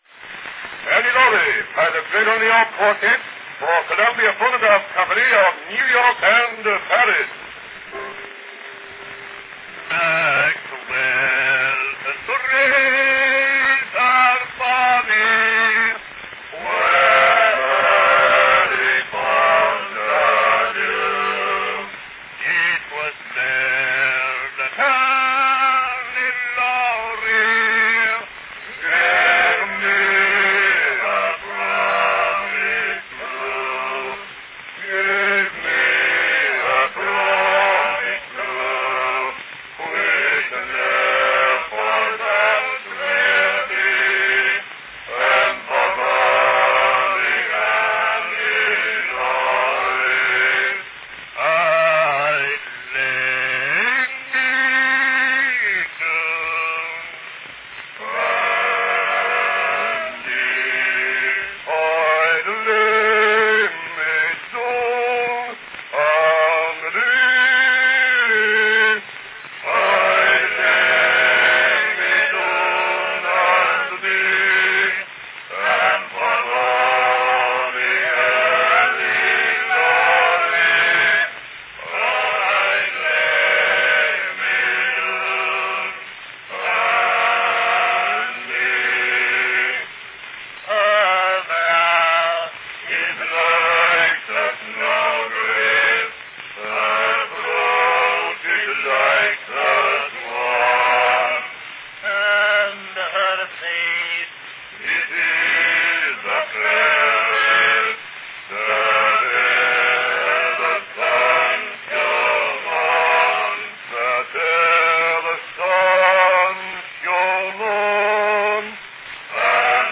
From 1899, a fine quartette rendition of the classic Scotch ballad, Annie Laurie.
Category Quartette
Performed by The Greater New York Quartette